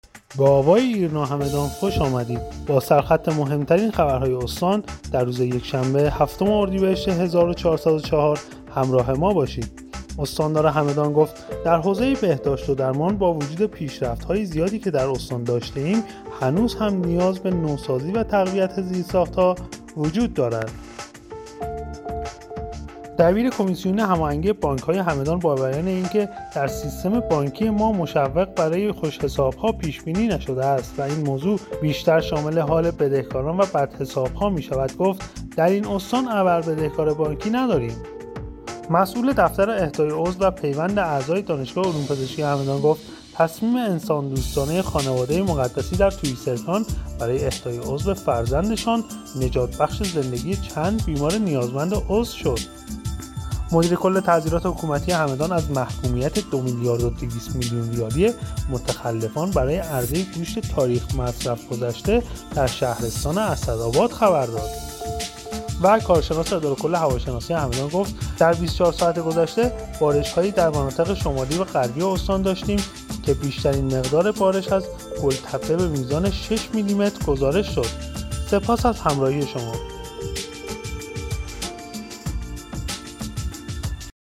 همدان-ایرنا- مهم‌ترین عناوین خبری دیار هگمتانه را هر شب از بسته خبر صوتی آوای ایرنا همدان دنبال کنید.